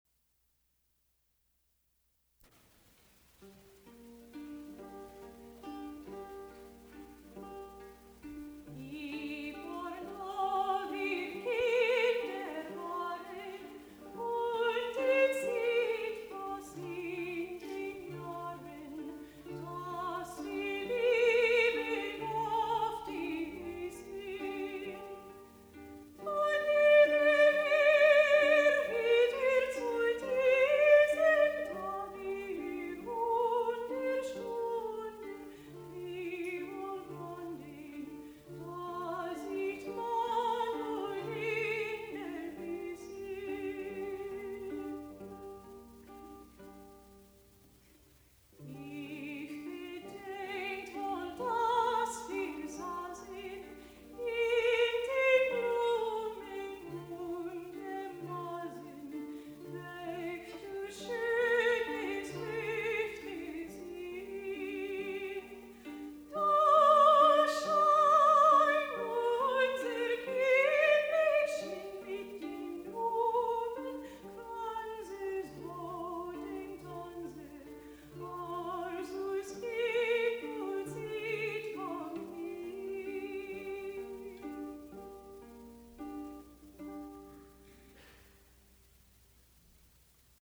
soprano
lute